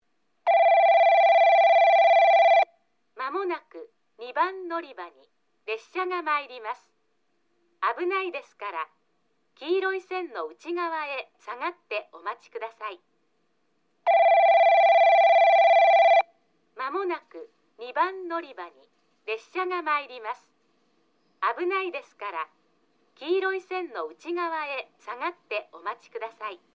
放送は上下とも1，3番のりばが男声、2，4番のりばが女声で固定されています。スピーカーは旧放送同様TOAラッパ型から流れ、クリアホーンからは遠隔放送が流れます。
2番のりば接近放送　女声